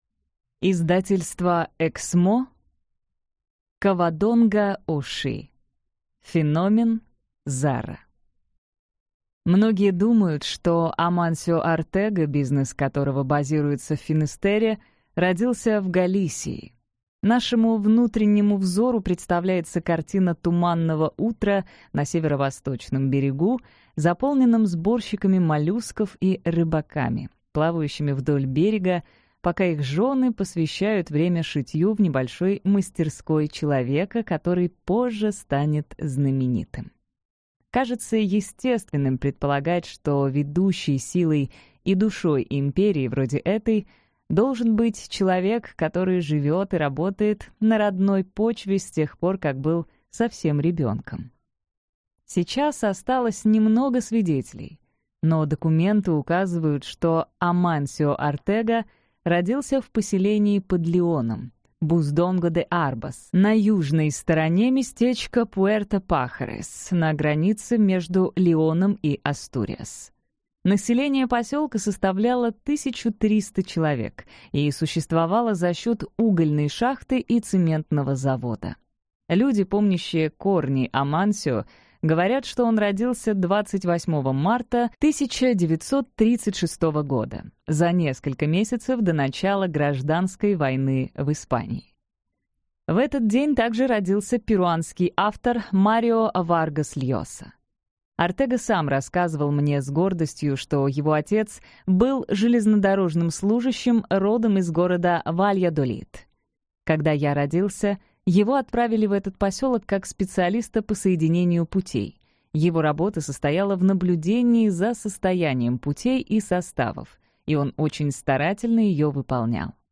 Аудиокнига Феномен ZARA | Библиотека аудиокниг